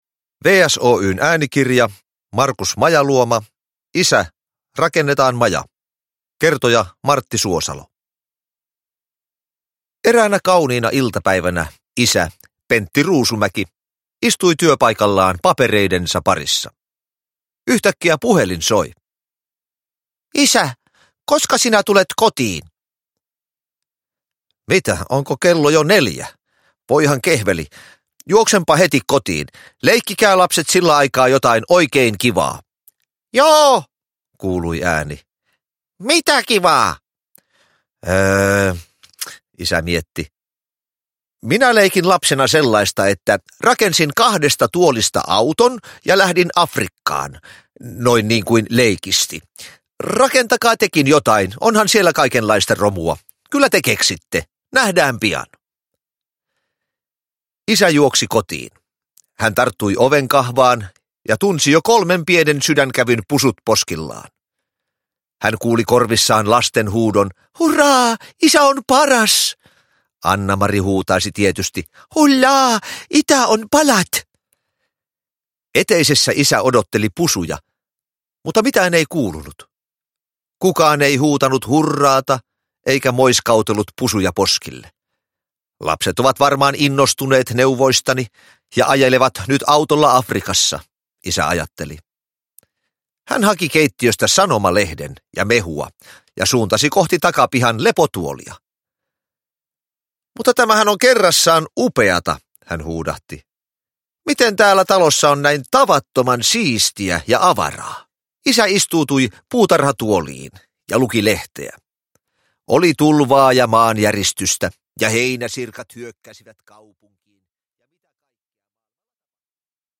Näyttelijä Martti Suosalo on kertojana sarjasta tehdyissä äänikirjoissa. Martti Suosalon kerronta tavoittaa tarinoiden riemukkaimmatkin vivahteet.
Uppläsare: Martti Suosalo